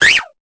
Cri de Bébécaille dans Pokémon Épée et Bouclier.